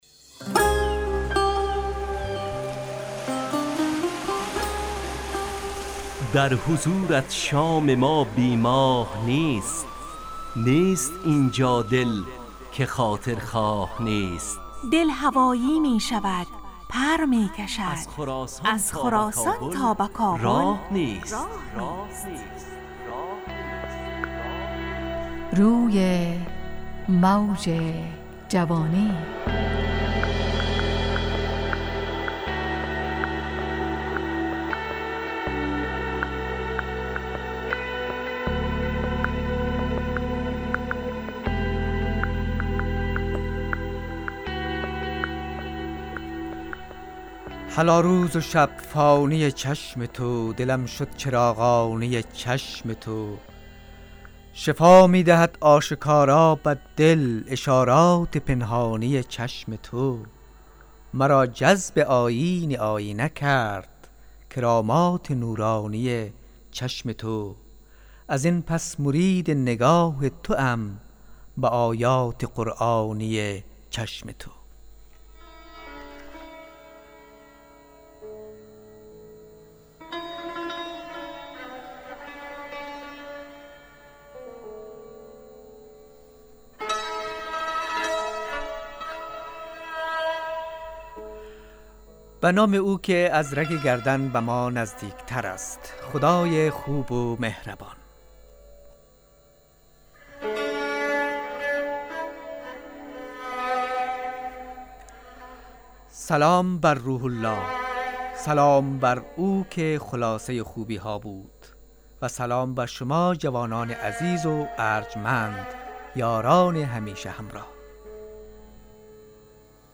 همراه با ترانه و موسیقی .